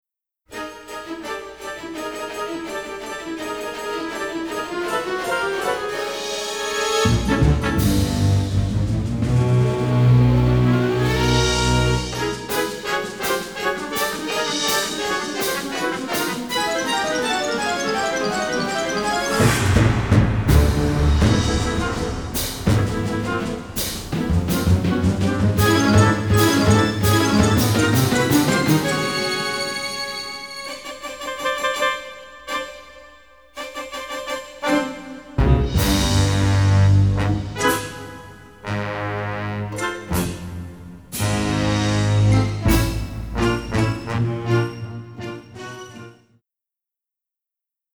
generous string section plus brass ensemble